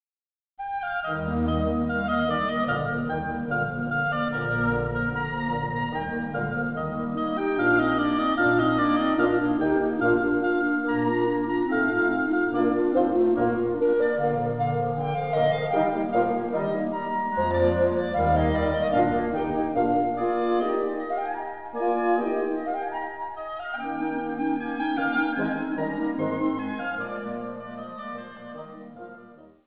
hautbois
clarinette
cor
basson